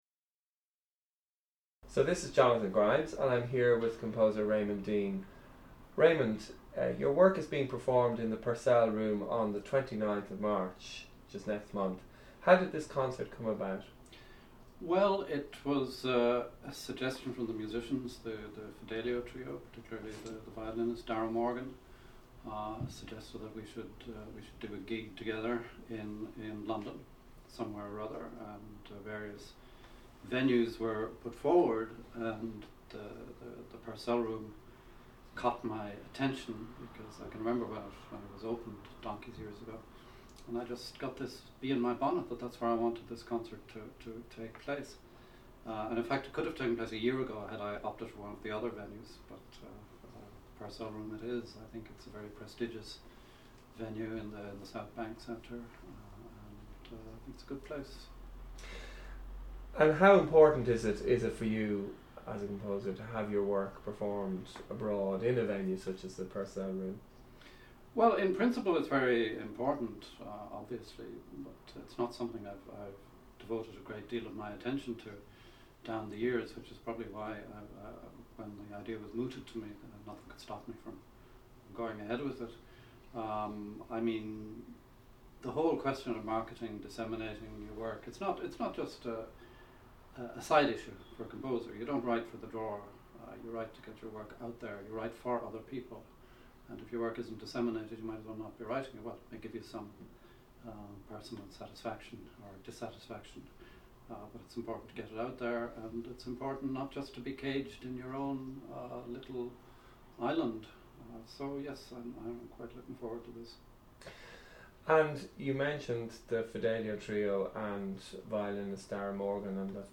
Interview with Raymond Deane